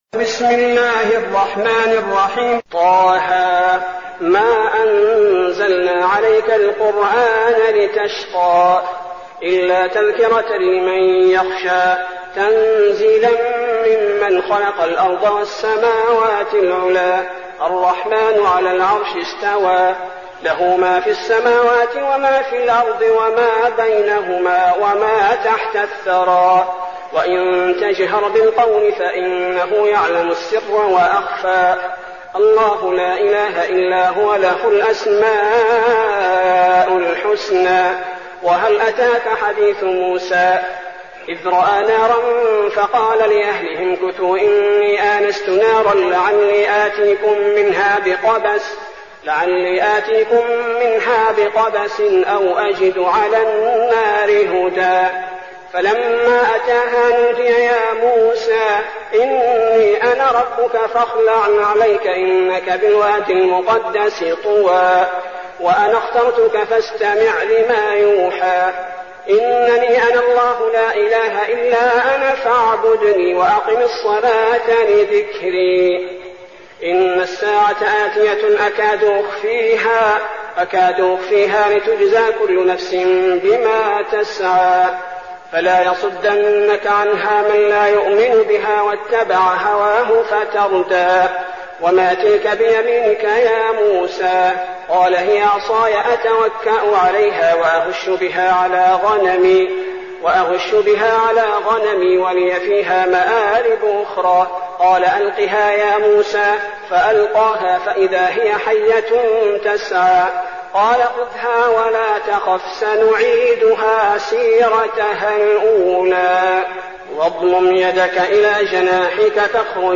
المكان: المسجد النبوي الشيخ: فضيلة الشيخ عبدالباري الثبيتي فضيلة الشيخ عبدالباري الثبيتي طه